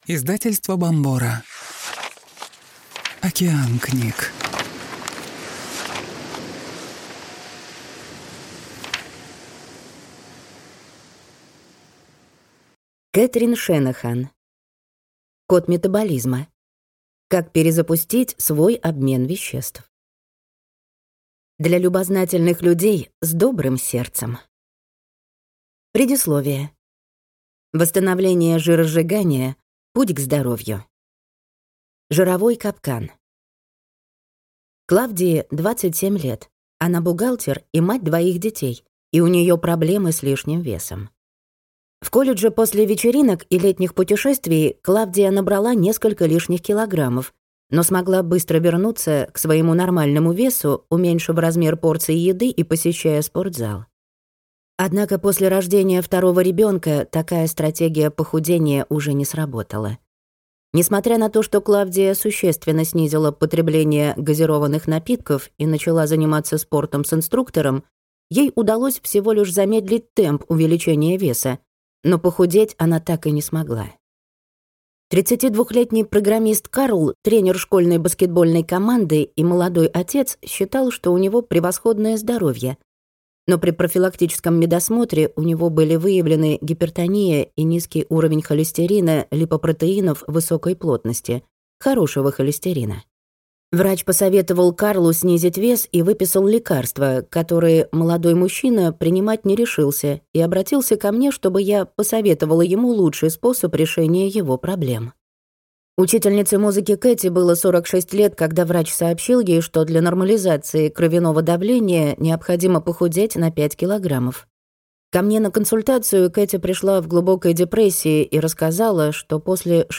Аудиокнига Код метаболизма. Как перезапустить свой обмен веществ | Библиотека аудиокниг